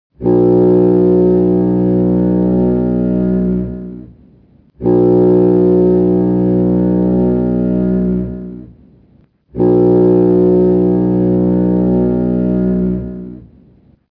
sireneTitanic.mp3